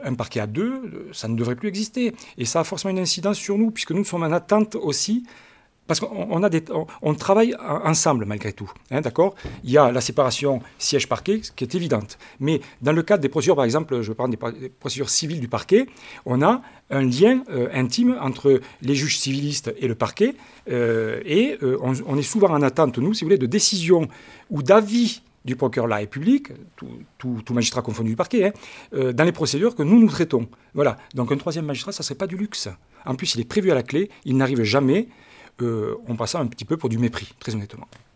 La rentrée solennelle du tribunal judiciaire de Mende a eu lieu hier matin dans la grande salle d’audience.
Une arrivée qui ne suffit pas à combler les besoins du parquet, déplore Yves Gallego, président du tribunal judiciaire de Mende.